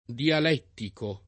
[ dial $ ttiko ]